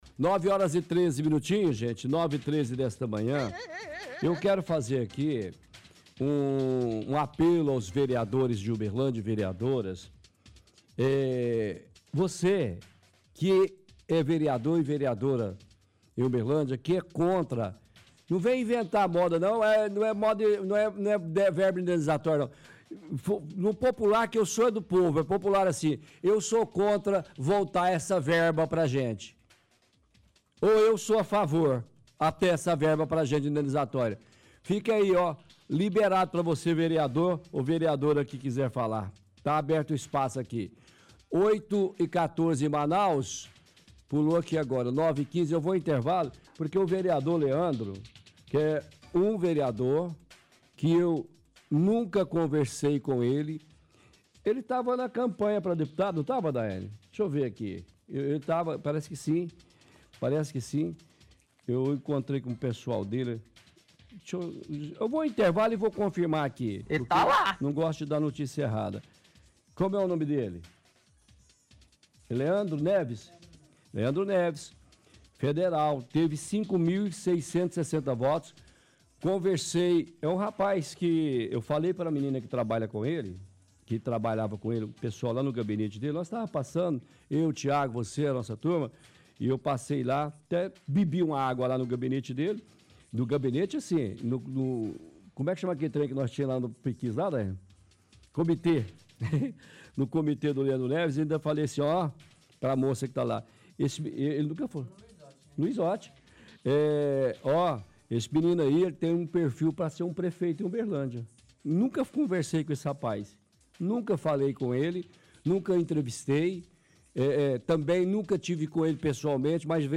– Transmissão de áudio do vereador Carrijo falando sobre verba indenizatória na câmara.